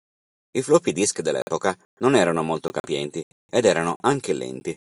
Pronounced as (IPA) /ˈlɛn.ti/